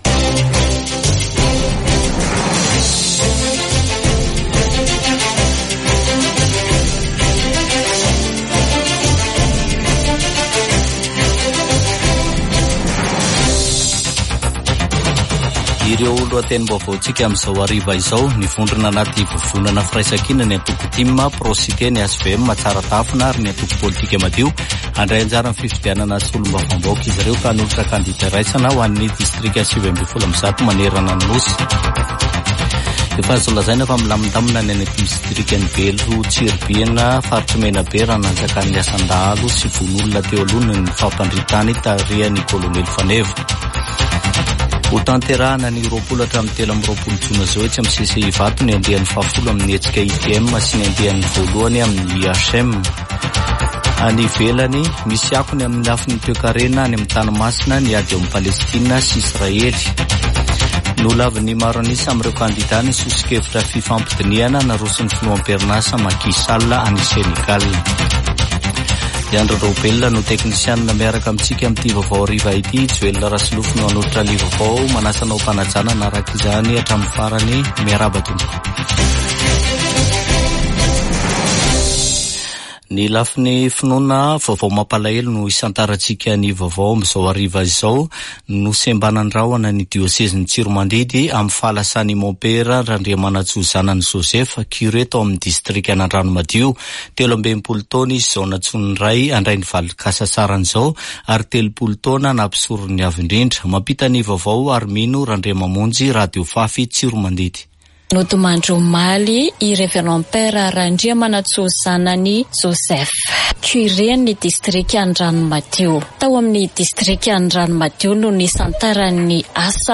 [Vaovao hariva] Zoma 23 febroary 2024